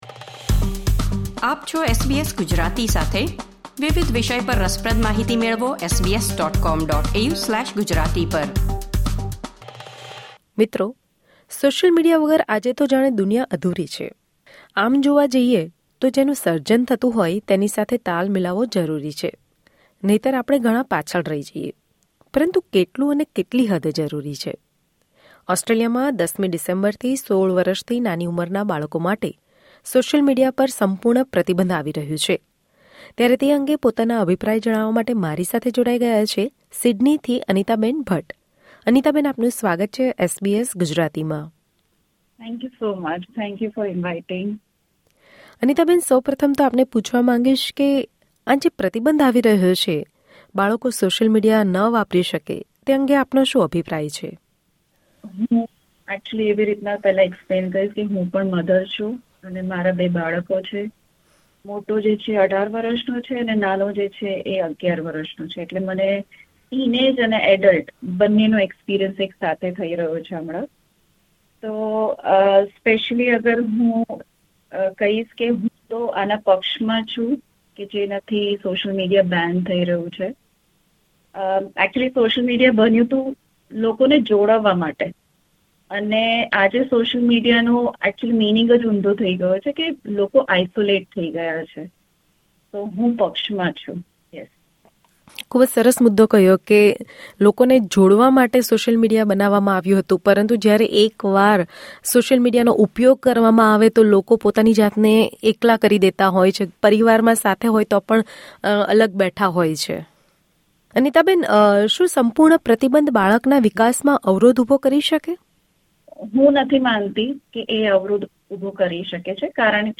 When social media was created, its purpose was to connect people, but today everyone has a mobile phone at home and everyone is so busy with social media that people are unable to connect with each other. Parents shared their views with SBS Gujarati on the ban on social media among children.